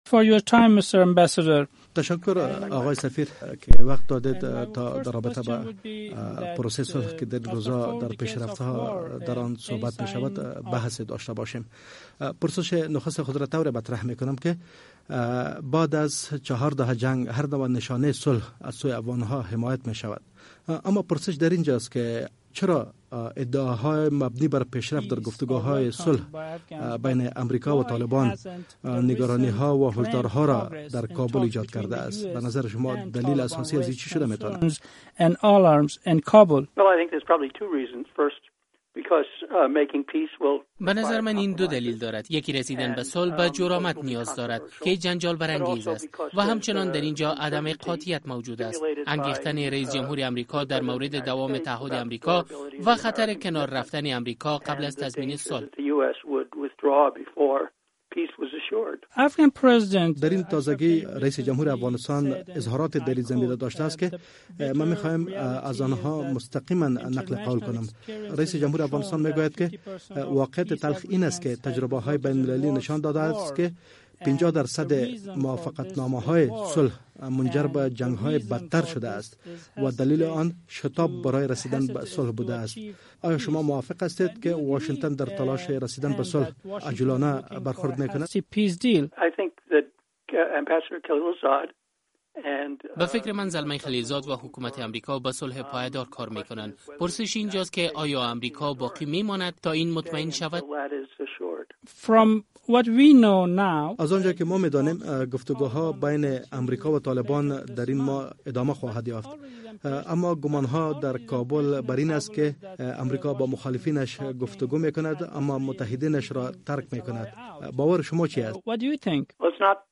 مصاحبه - صدا
جیمز دابنز در مصاحبۀ اختصاصی با رادیو آزادی گفت، روسیه می‌خواهد خود را در گفتگوهای صلح افغانستان شامل احساس کند.